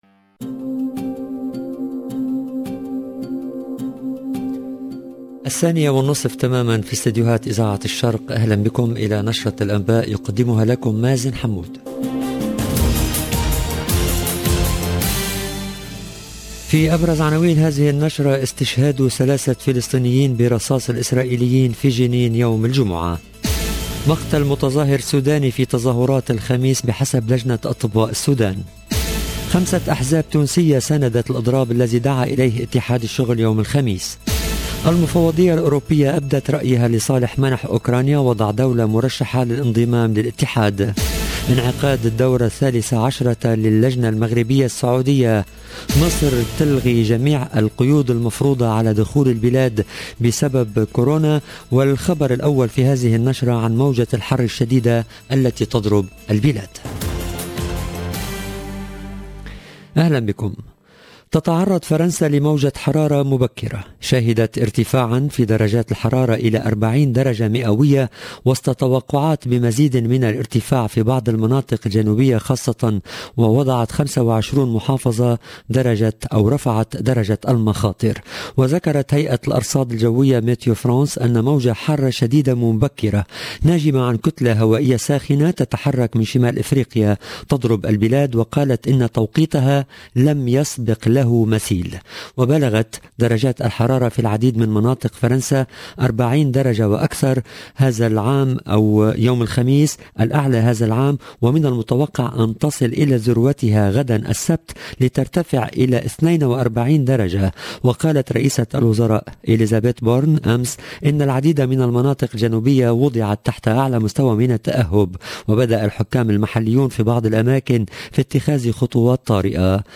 EDITION DU JOURNAL DE 14H30 EN LANGUE ARABE DU 17/6/2022